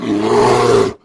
bear.wav